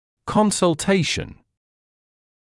[ˌkɔnsl’teɪʃn][ˌконсл’тэйшн]консультация